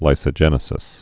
(līsə-jĕnĭ-sĭs)